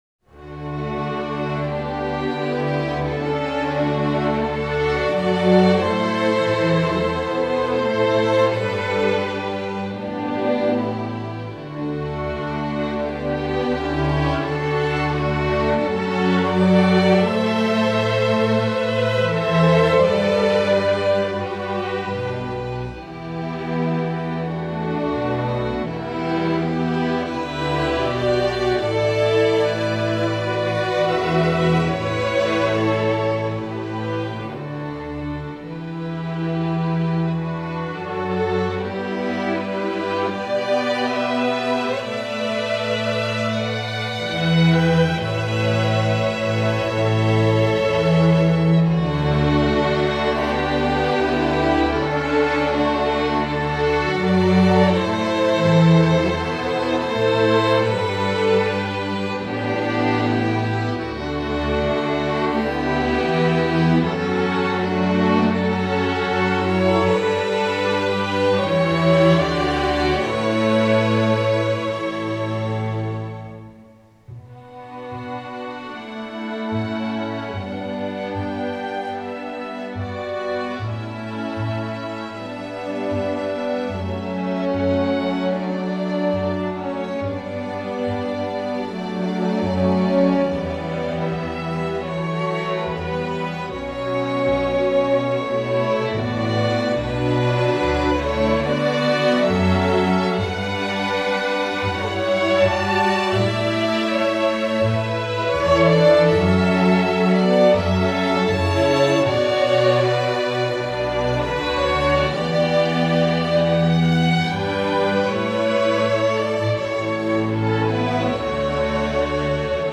Instrumental Orchestra String Orchestra
String Orchestra